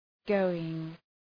{‘gəʋıŋ}